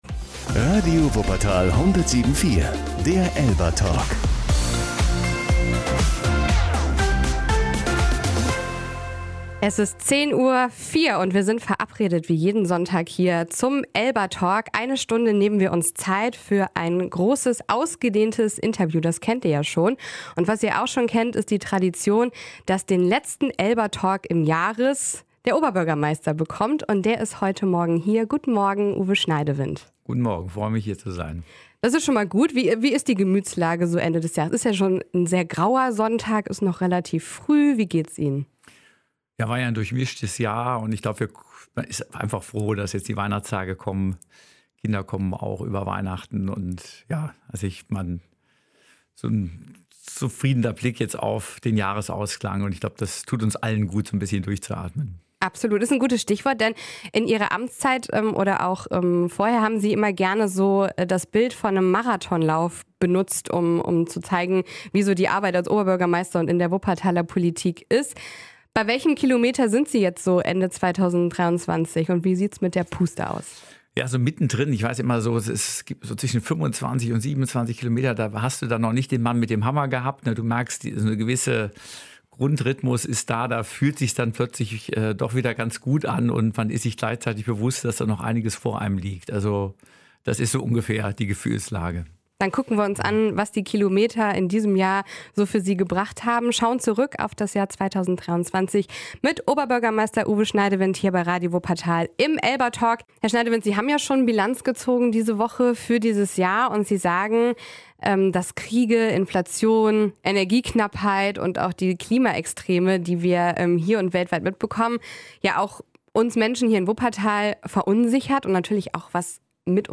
Oberbürgermeister Uwe Schneidewind sagt im ELBA-Talk, dass bei den vielen Krisen und Problemen weltweit das Positive und die Fortschritte in Wuppertal nicht vergessen werden dürfen. Den neuen BOB-Campus und das Schwebodrom wertet er als Erfolge, beim Elberfelder Wochenmarkt und den City-Baustellen sieht er Gesprächs-und Handlungsbedarf und in den Finanzen und dem Fachkräftemangel Aufgaben für mehrere Jahre.